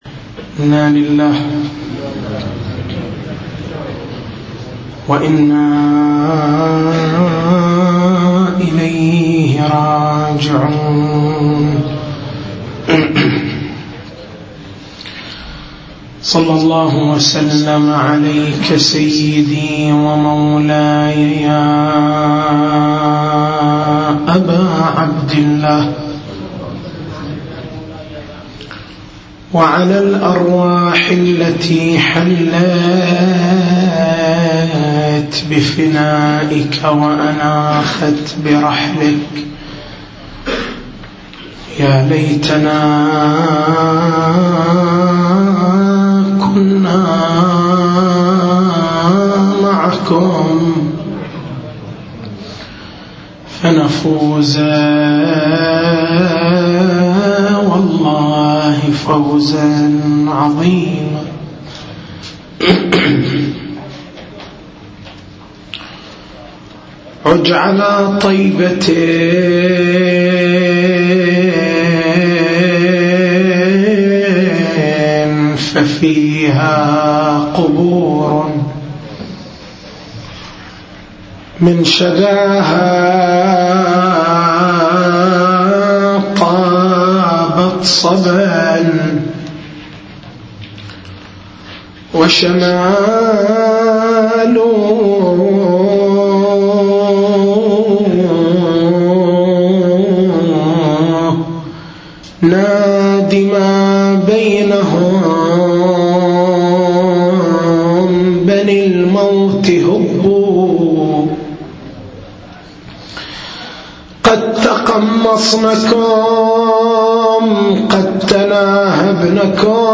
مكتبة المحاضرات